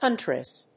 • / hˈʌntrəs(米国英語)